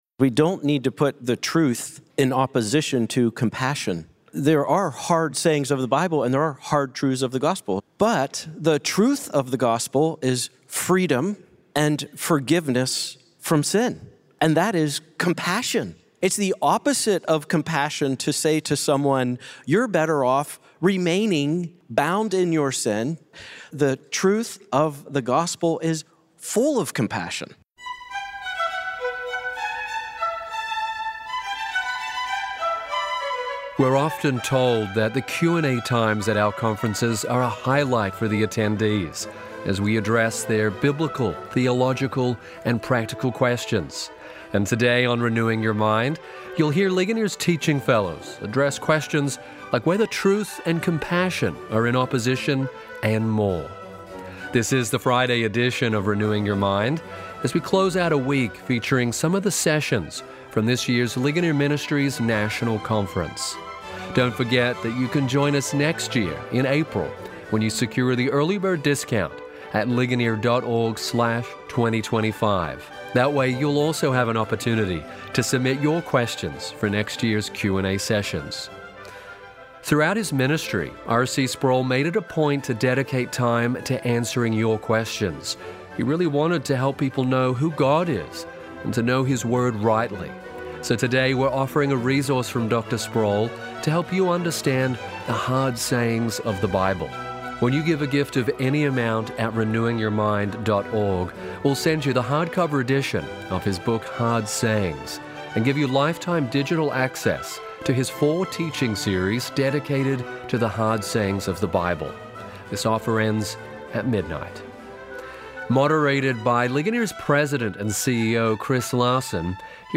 What false teachings threaten the church today? How can Christians speak the truth without compromising compassion? Today, the Ligonier Teaching Fellows address biblical and theological questions from guests attending the 2024 Ligonier National Conference.